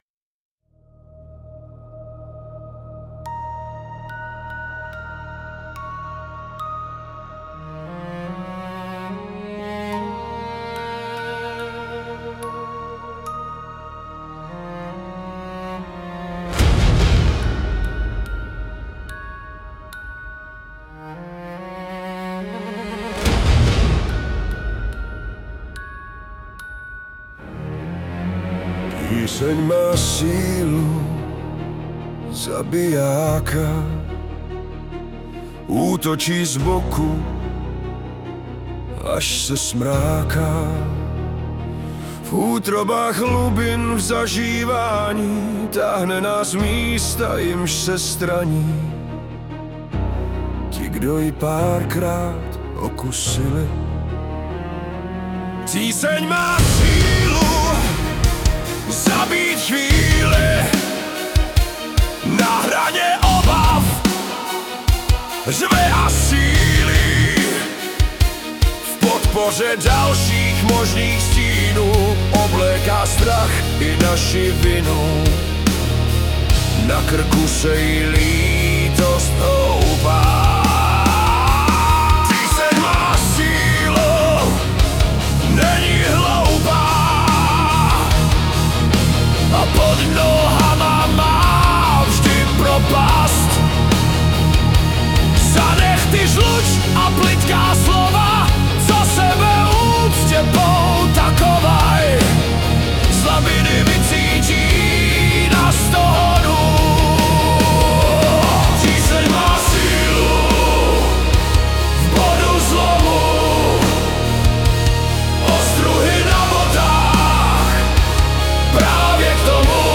Anotace: druhá verze zhudebnění - mám ji ráda, v autě si jí pouštím víc / k dnešním démonům je to dobrá paralela
**zpívá si a říkám si, jak tahle ostřejší verze k tomu fakt víc sedí